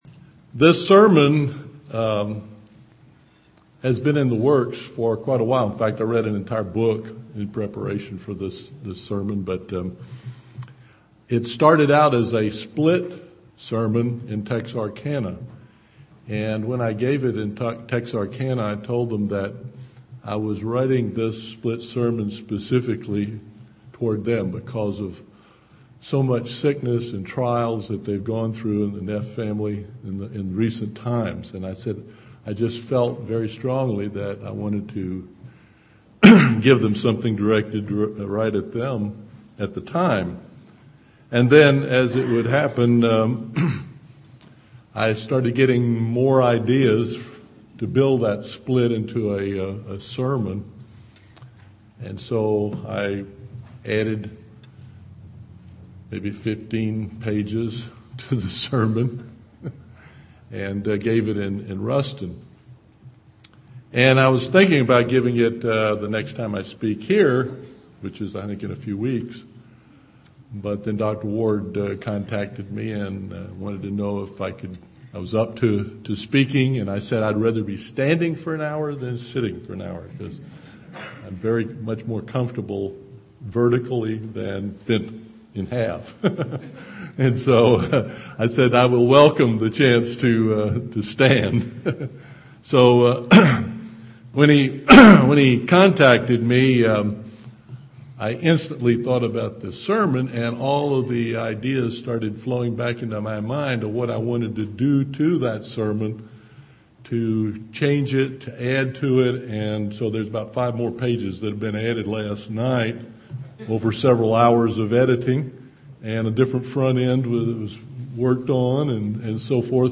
Given in East Texas
UCG Sermon Studying the bible?